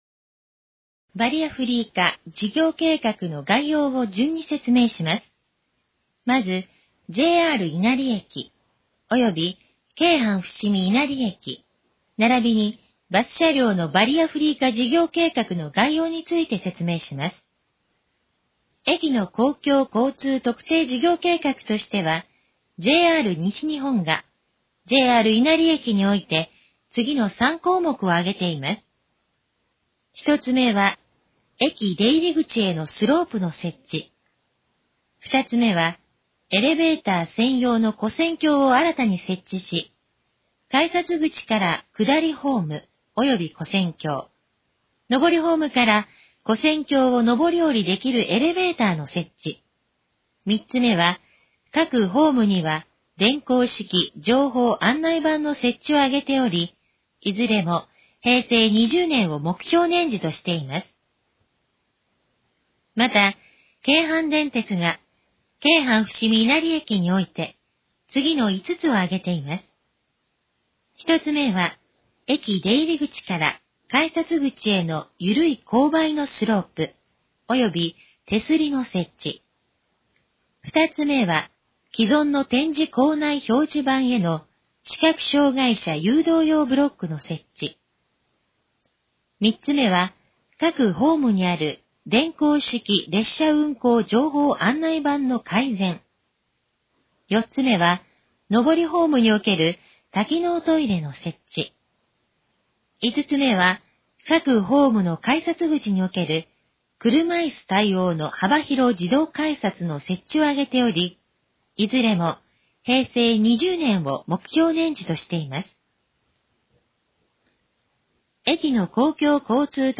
以下の項目の要約を音声で読み上げます。
ナレーション再生 約540KB